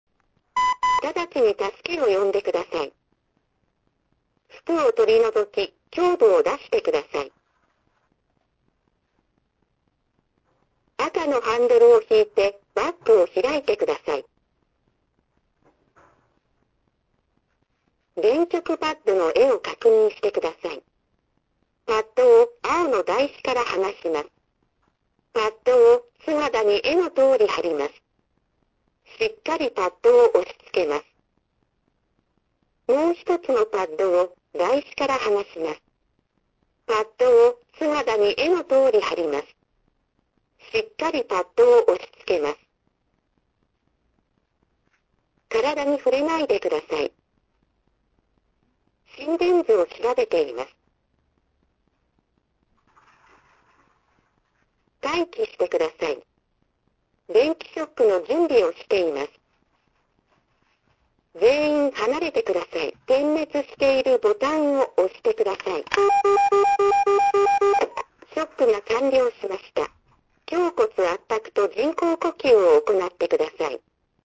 ＡＥＤ(LPCRPlus)によるショック完了までの音声ガイダンス